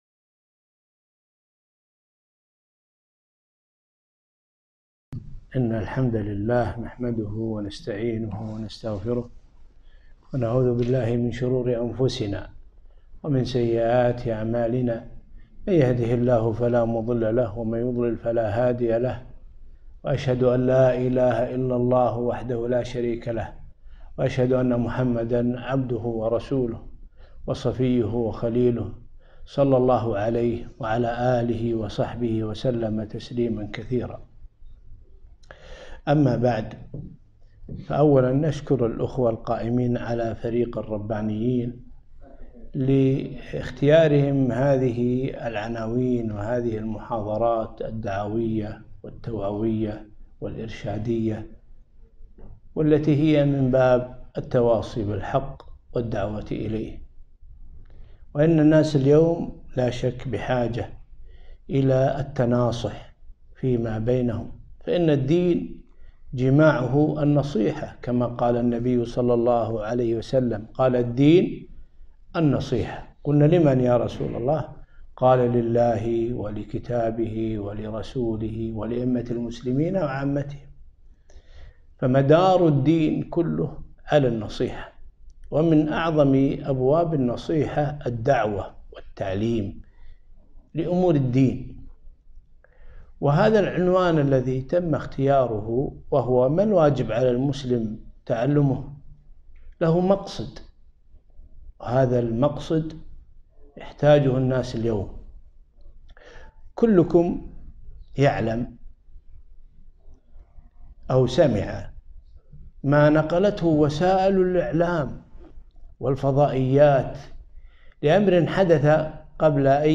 محاضرة - ما الواجب على المسلم تعلمه ؟